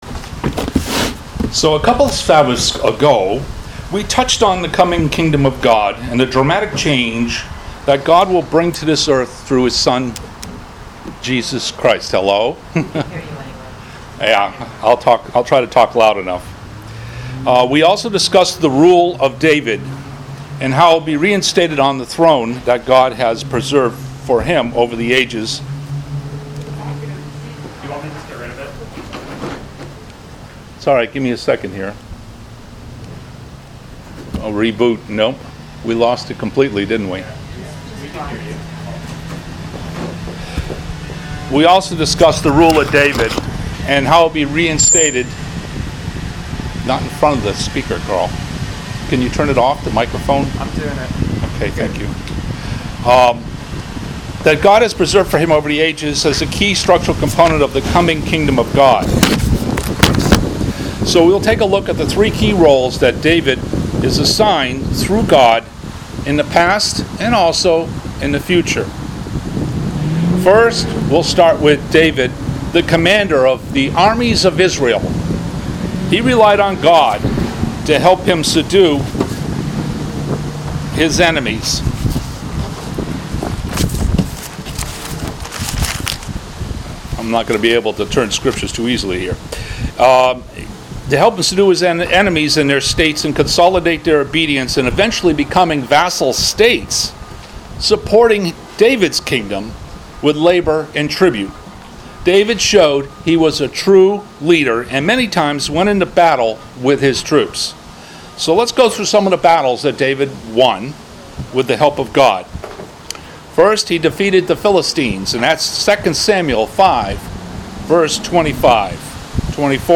Given in Hartford, CT